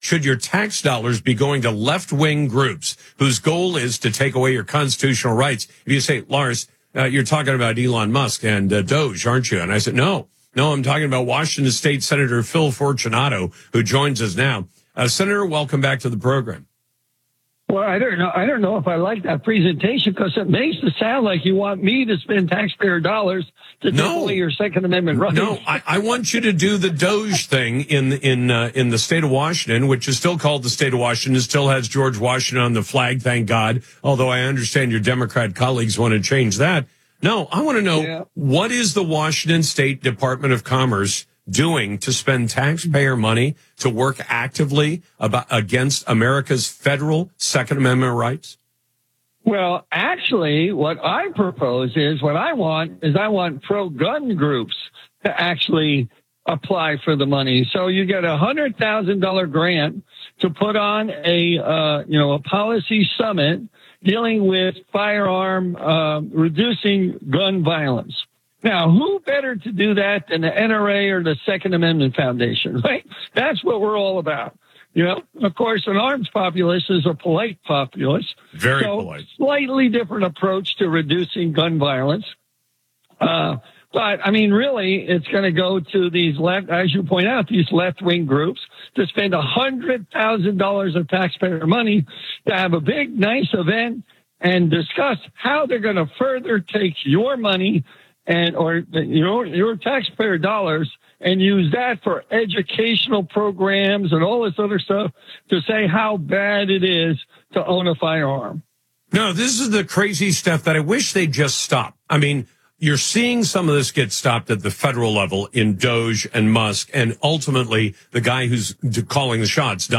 State Senator Phil Fortunato joins Lars Larson to expose taxpayer-funded grants supporting left-wing agendas, including anti-Second Amendment initiatives and controversial social programs. He argues for redirecting funds and urges conservatives to scrutinize government spending.